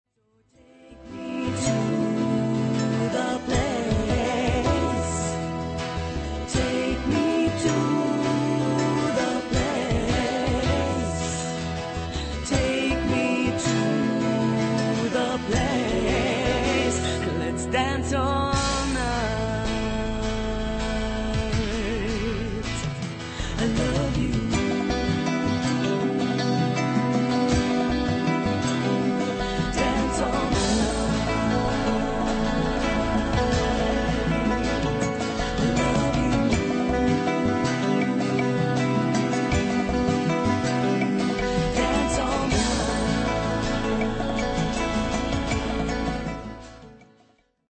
original pop music
from folk and pop to jazz influences.